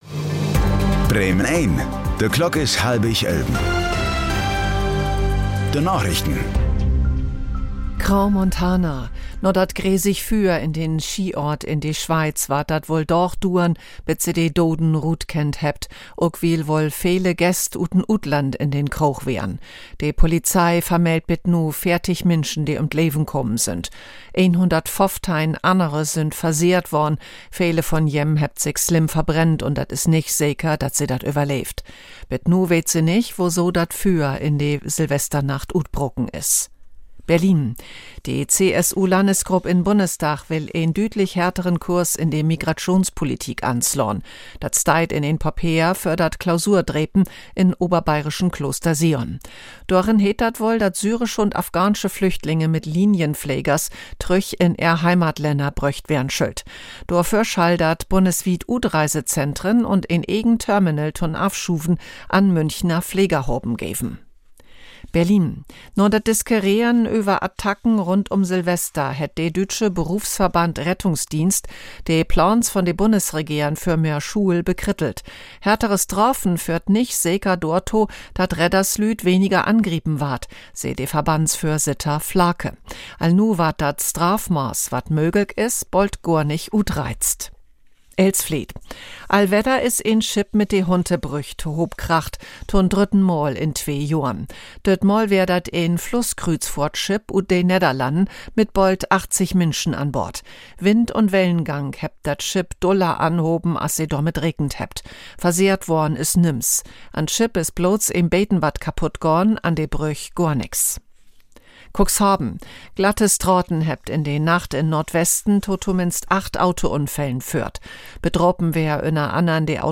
Plattdüütsche Narichten vun'n 2. Januar 2026